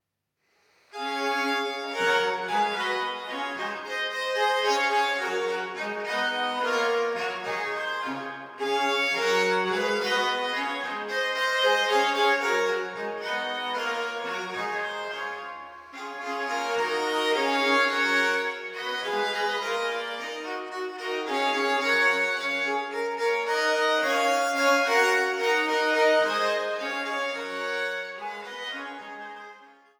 Kleindiskantgeige
Diskantgeige
Tenorgeige
Bassgeigen
Sopran